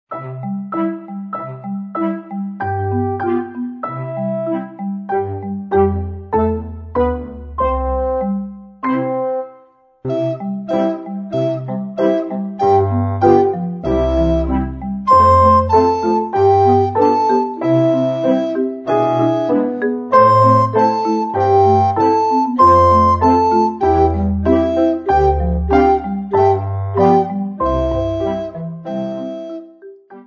Besetzung: Sopranblockflöte